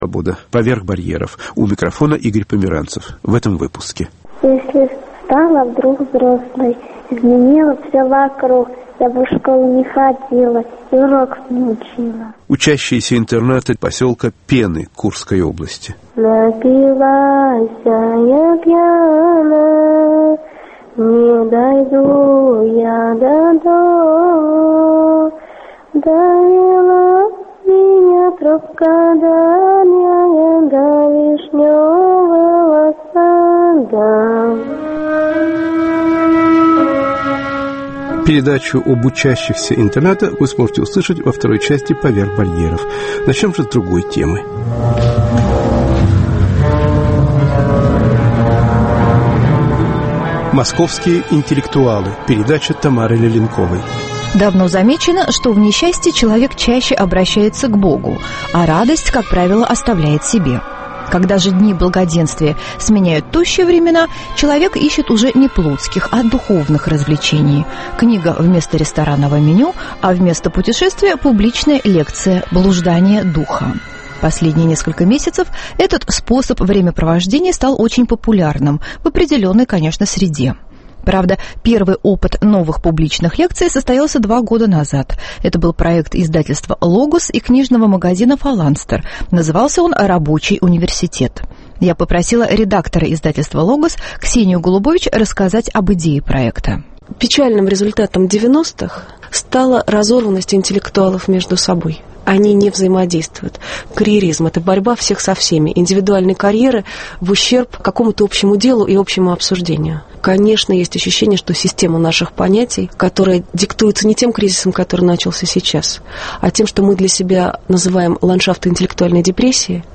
"Московские интеллектуалы"- фрагменты из лекций московских гуманитариев, включая одну из последних записей Натальи Трауберг (1928-2009).